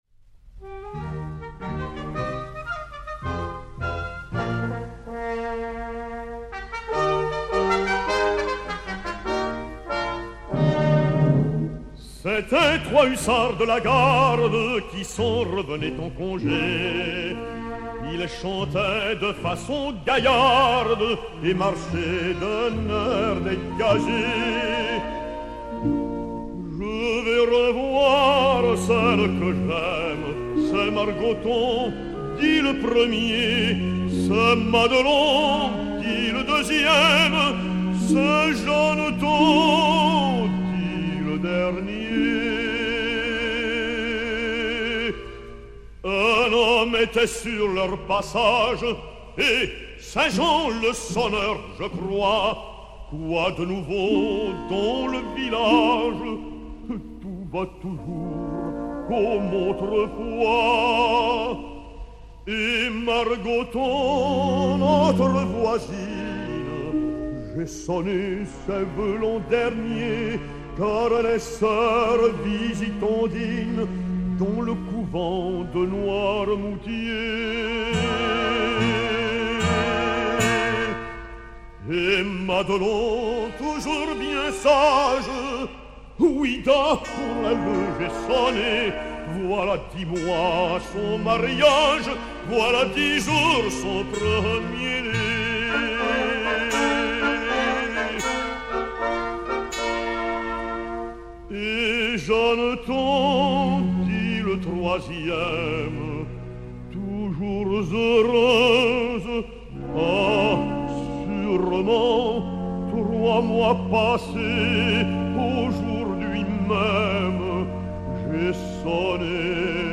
baryton-basse français